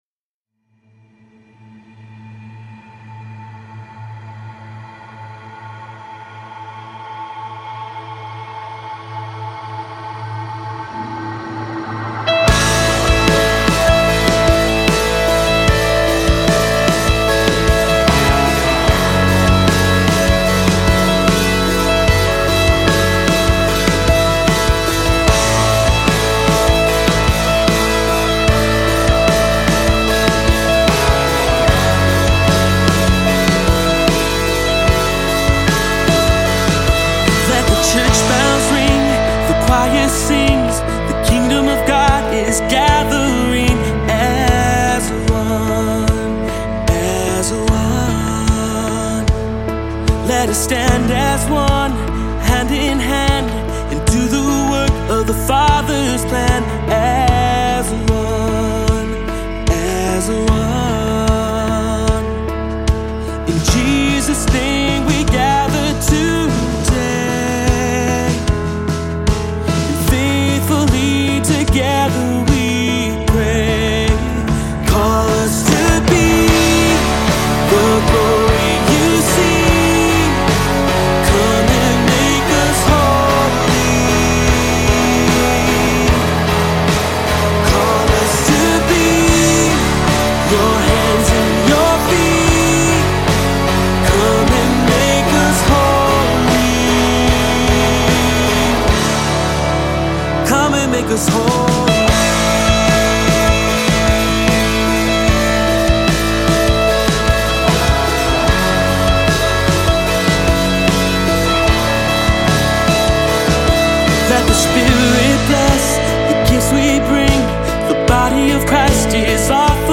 Voicing: 2-part Choir, assembly, cantor